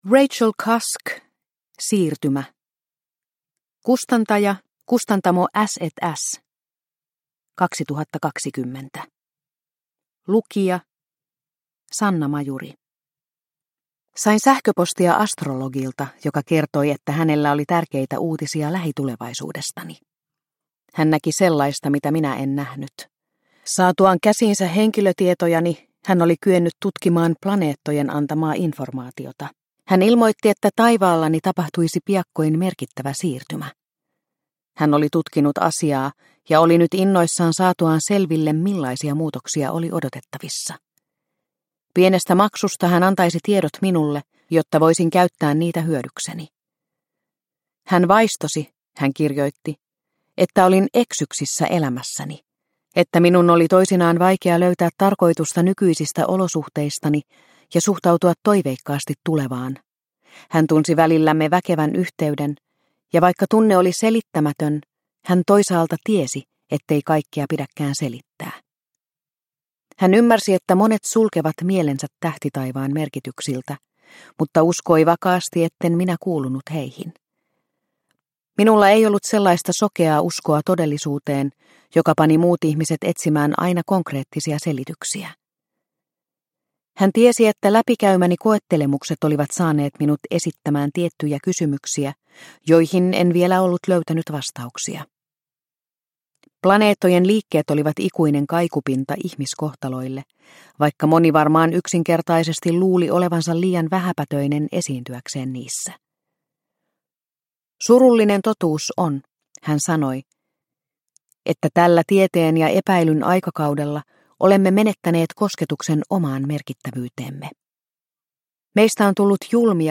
Siirtymä – Ljudbok – Laddas ner